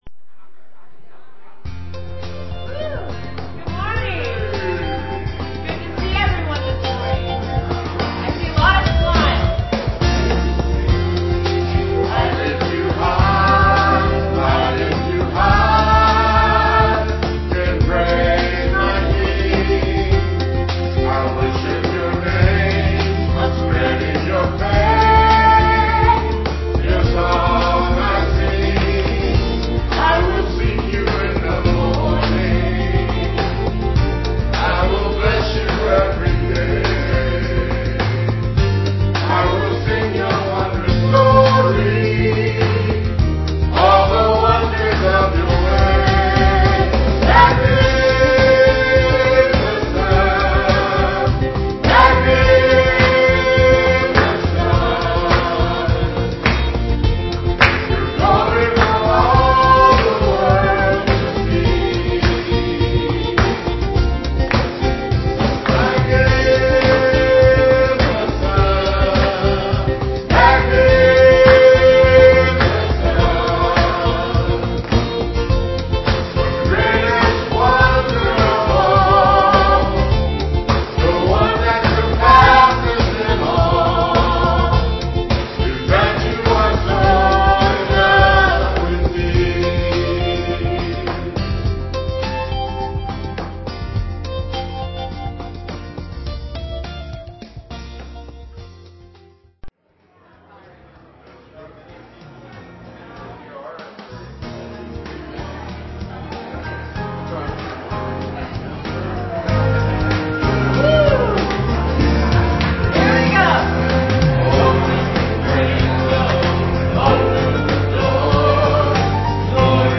Piano and organ offertory